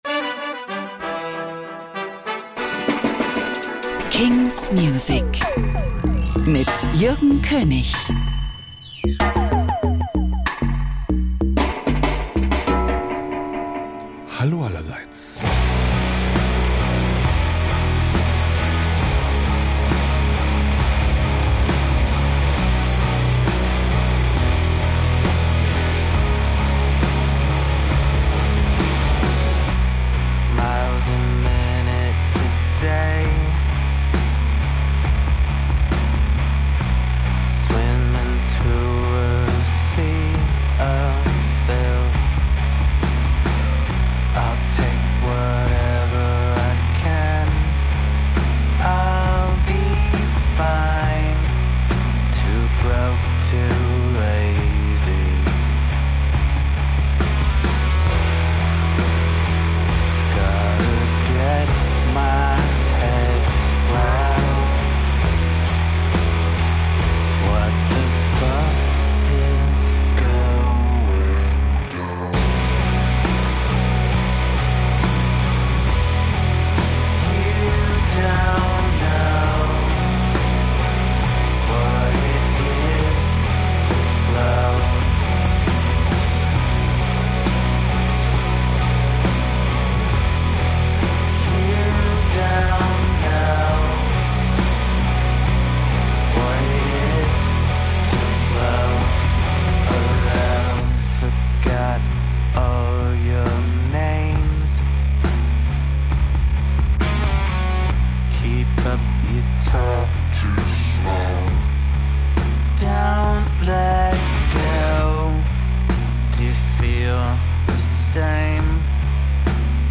brandnew indie & alternative releases